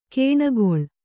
♪ kēṇagoḷ